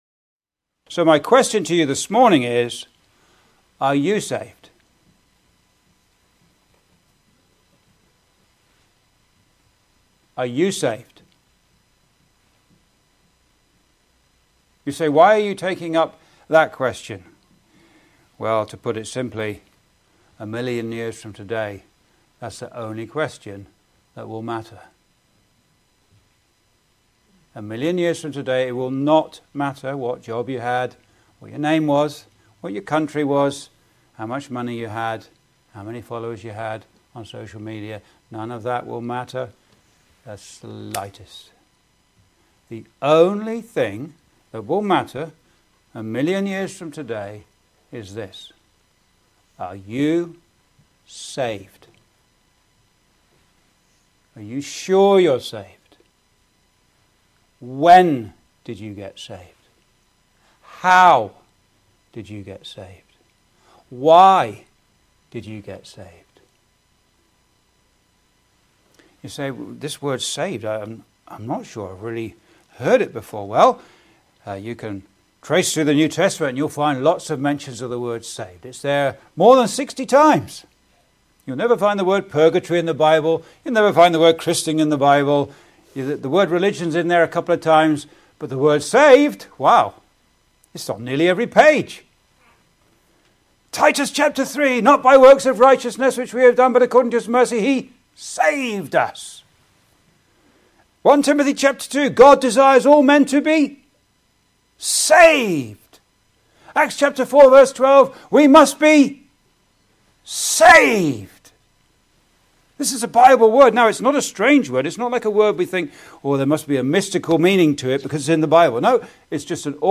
Gospel Sermons